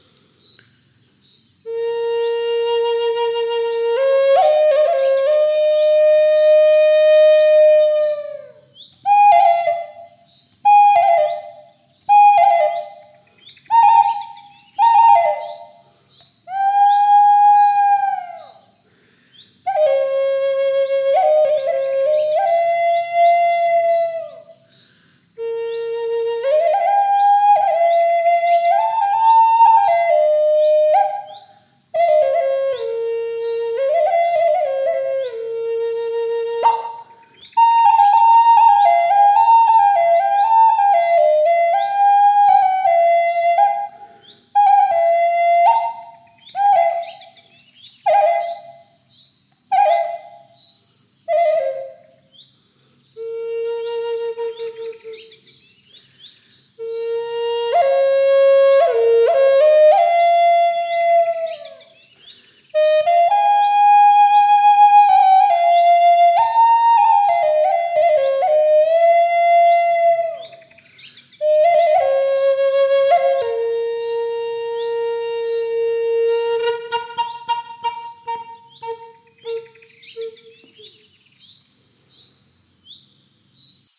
High Lakota flute in key of B (si) – Avaye Lotus
Walnut wood, approximately 45cm long, with a stable coating and resistant to environmental factors, along with a bag, birth certificate, one-year warranty and free shipping.
Lakota-key-si-high.mp3